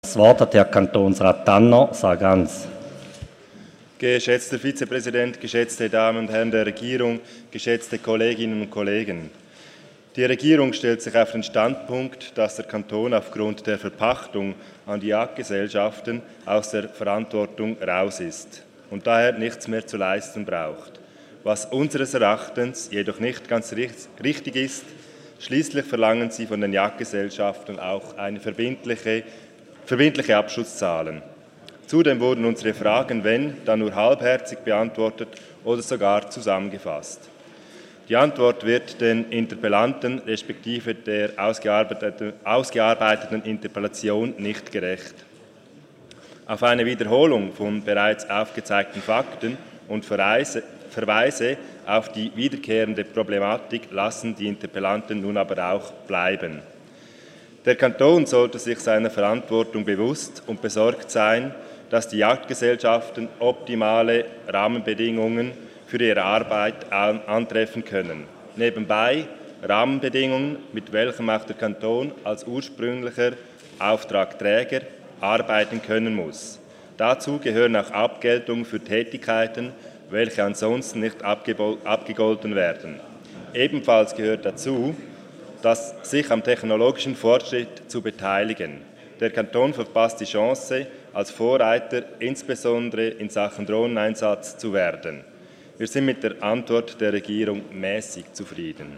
27.11.2019Wortmeldung
Sprecher: Tanner-Sargans
Session des Kantonsrates vom 25. bis 27. November 2019